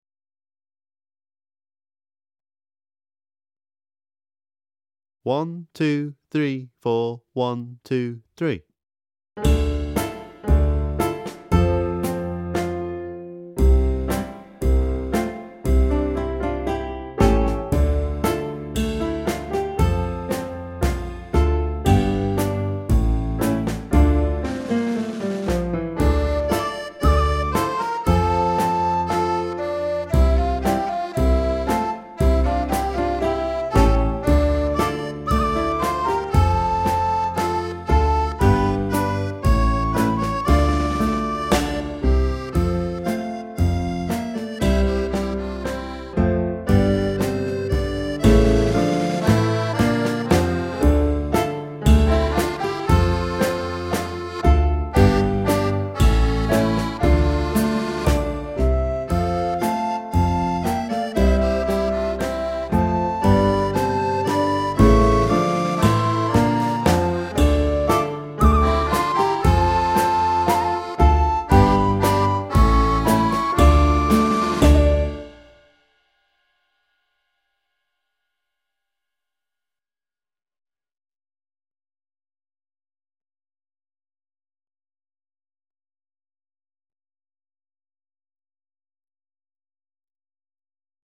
35 Morag's Medicine (Backing Track)